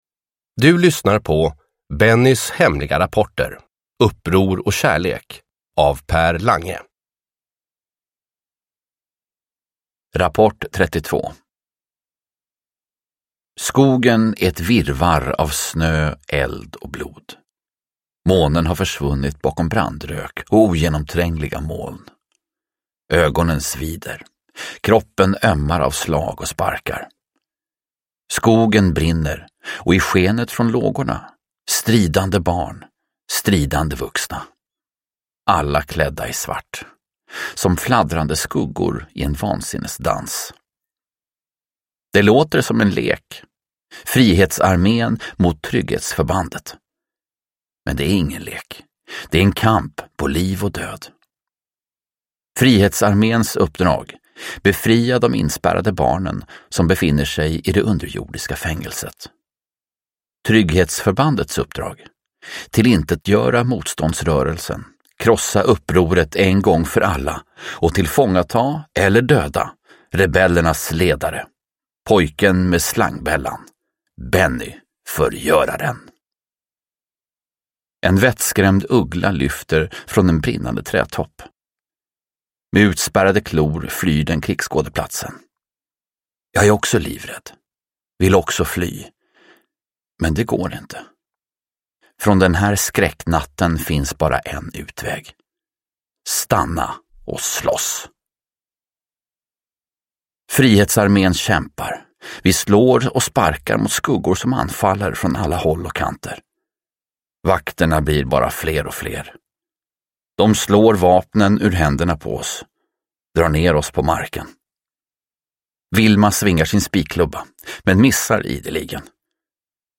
Uppror och kärlek – Ljudbok